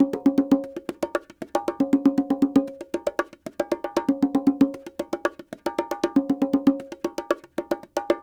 44 Bongo 04.wav